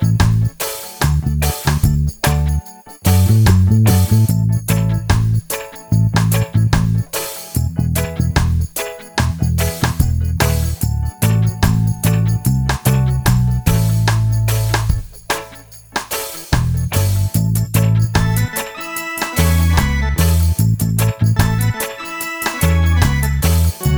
minus guitars no Backing Vocals Reggae 3:01 Buy £1.50